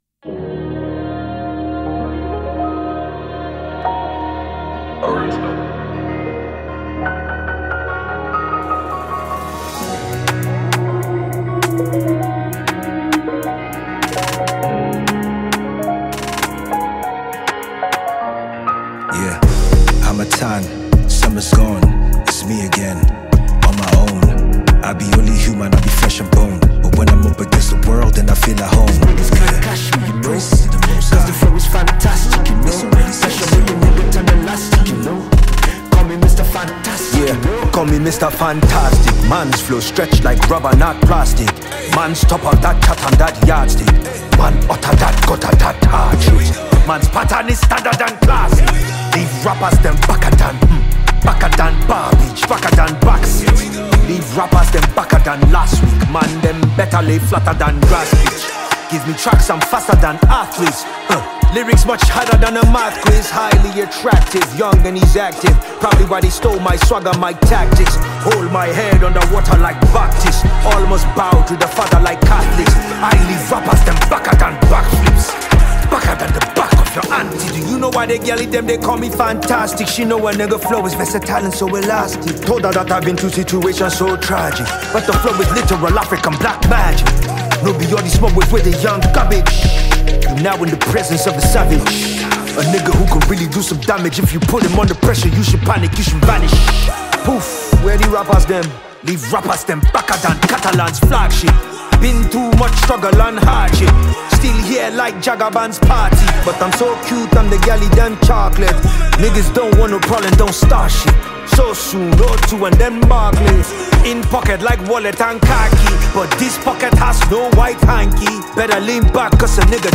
Veteran Rapper and Lyricist
” delivering his signature lyrical prowess and sharp flows.
real rap